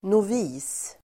Ladda ner uttalet
novis substantiv, novice Uttal: [nov'i:s] Böjningar: novisen, noviser Synonymer: färsking, nybörjare Definition: nybörjare (beginner) novice substantiv, novis [kyrkligt], nybörjare , novis , adept , elev , lärjunge